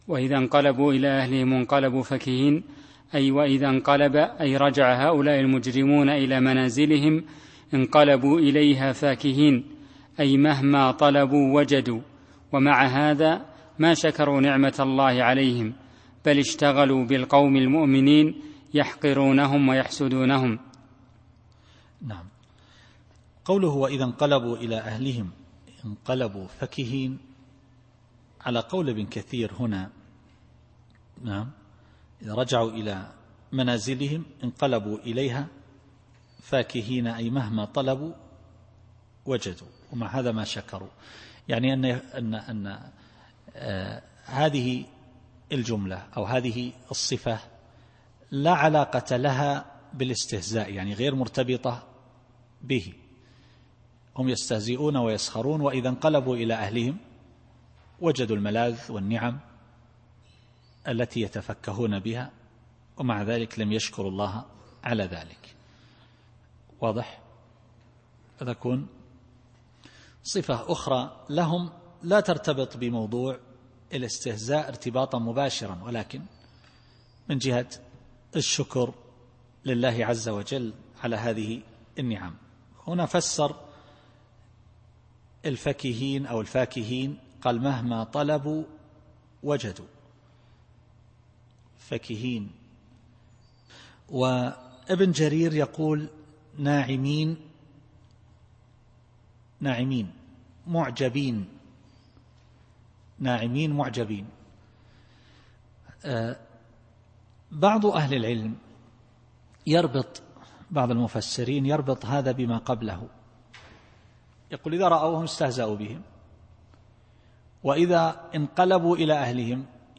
التفسير الصوتي [المطففين / 31]